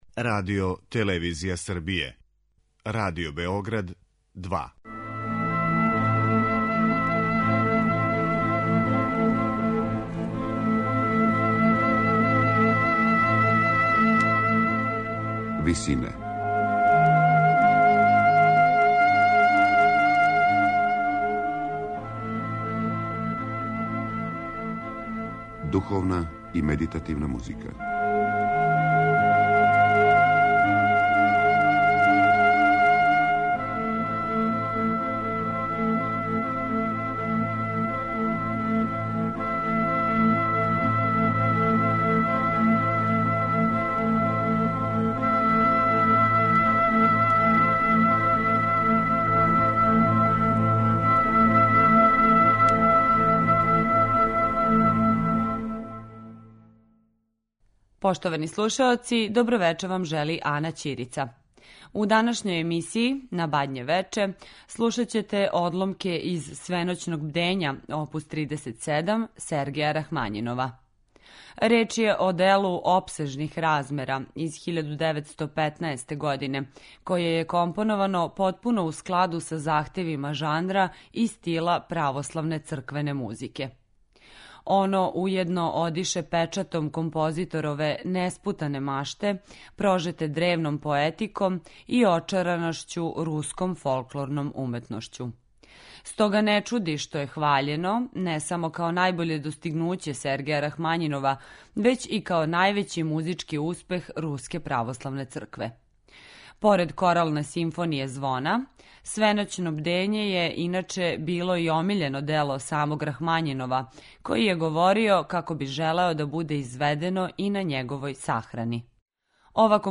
У данашњој емисији која је посвећена духовној и медитативној музици, на Бадње вече ћете слушати одломке из Свеноћног бдења оп. 37, Сергеја Рахмањинова.
Реч је о остварењу опсежних размера из 1915. године, које је компоновано потпуно у складу са захтевима жанра и стила православне црквене музике.